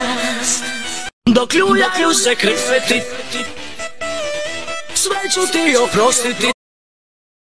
Kawałek z tiktoka - Muzyka elektroniczna